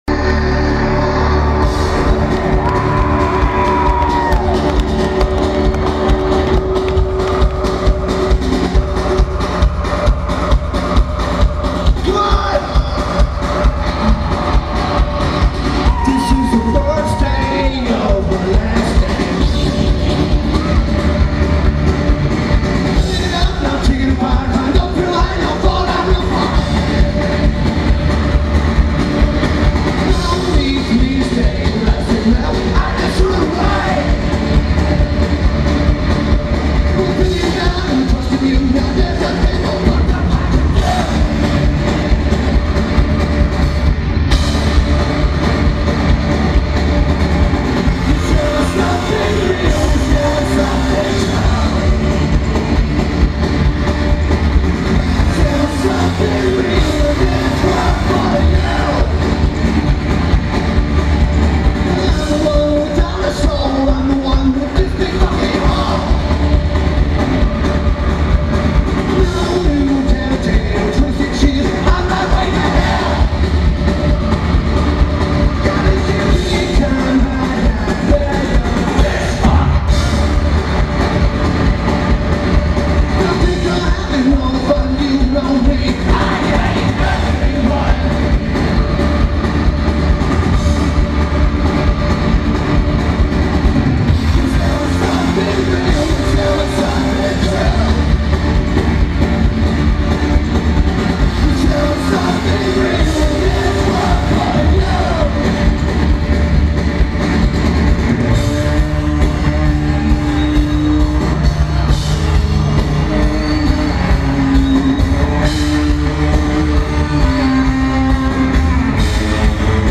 Starlake Amphitheater